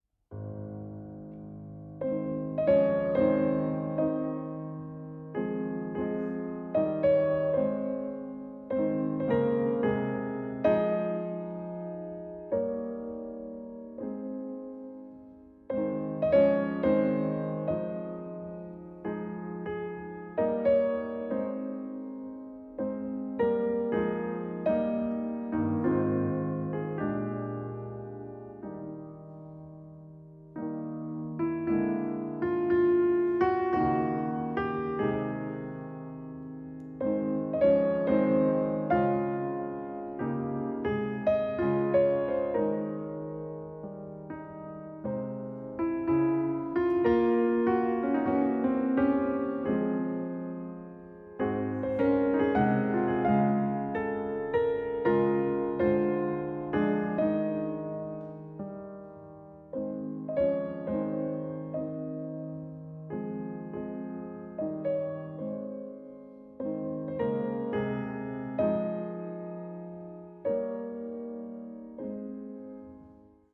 piano
Lent, lyrique